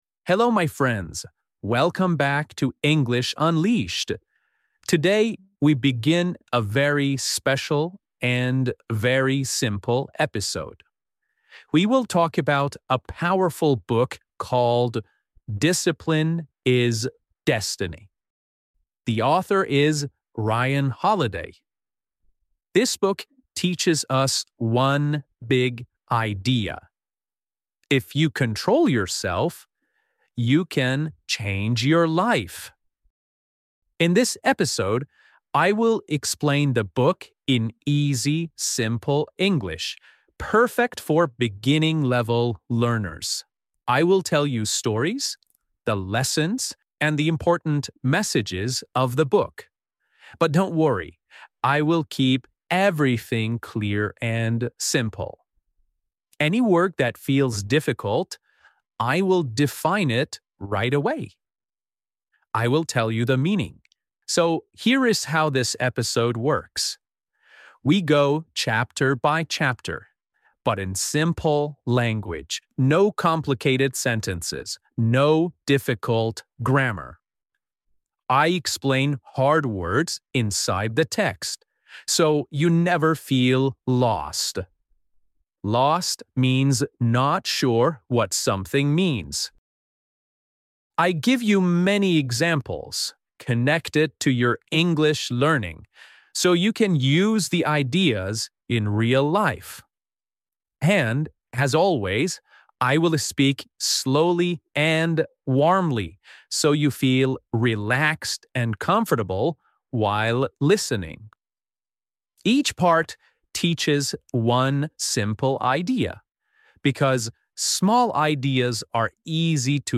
This beginner-friendly episode explains Discipline Is Destiny by Ryan Holiday in slow, easy English—perfect for A1–A2 learners.